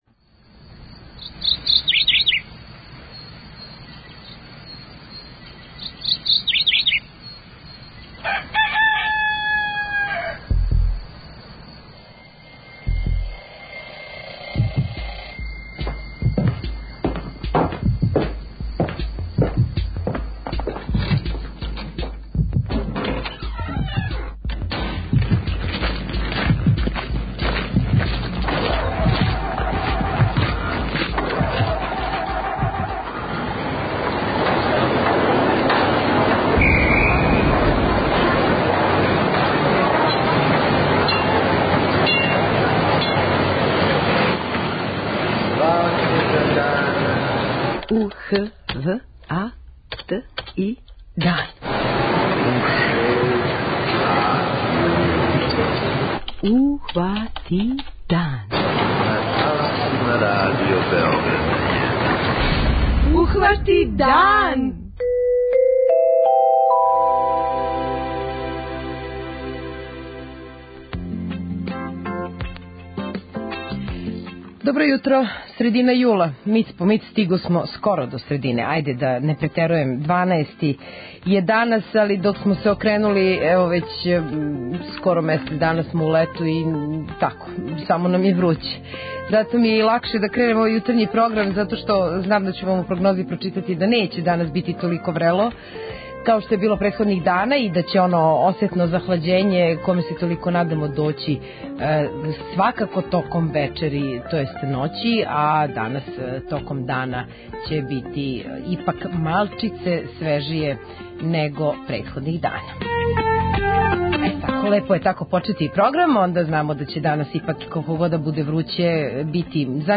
преузми : 21.58 MB Ухвати дан Autor: Група аутора Јутарњи програм Радио Београда 1!